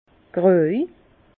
Bas Rhin gröj